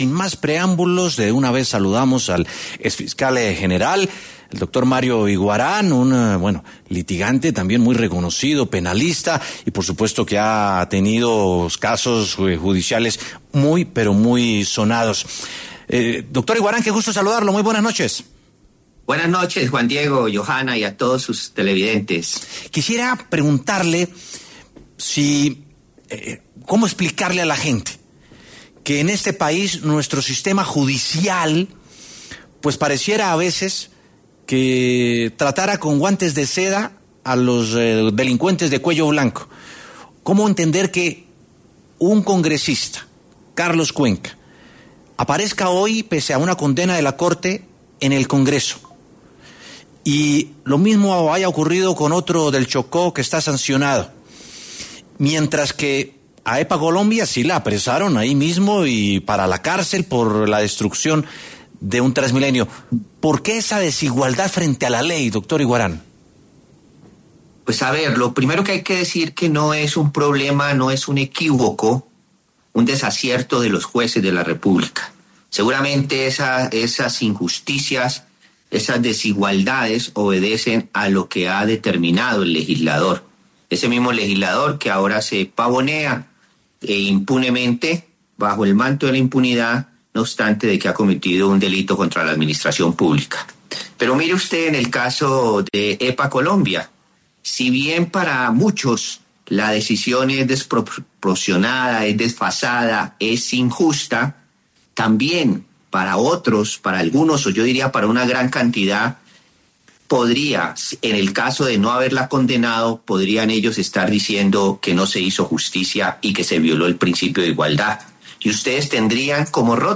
Mario Iguarán, exfiscal, se refirió en W Sin Carreta a la captura de la influenciadora Epa Colombia y la polémica que desató frente al proceder de la justicia colombiana, que desde algunos sectores se calificó como “desigual”.